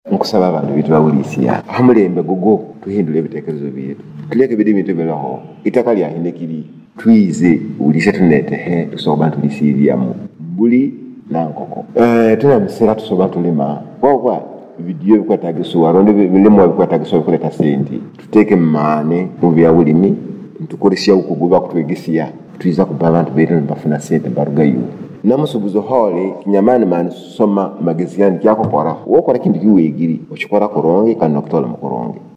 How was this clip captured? during an interview at his office this morning.